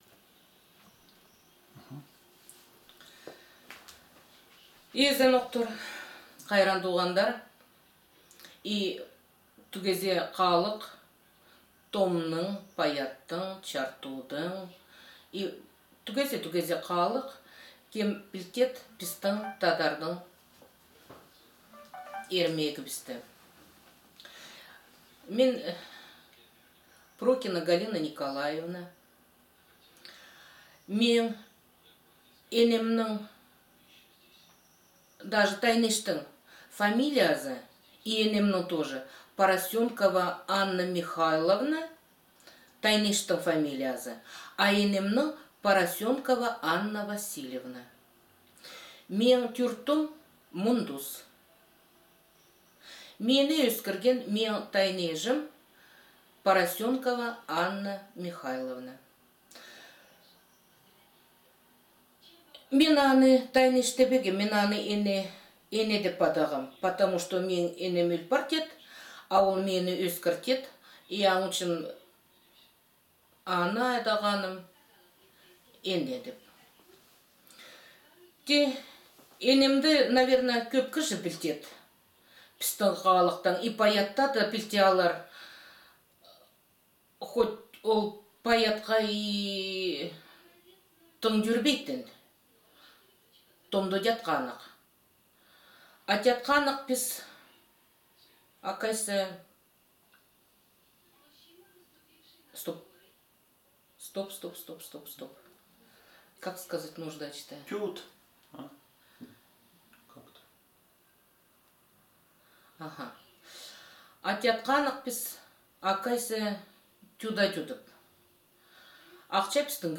2. Аудиозаписи речи
Рассказ на телеутском (расшифровку см. выше)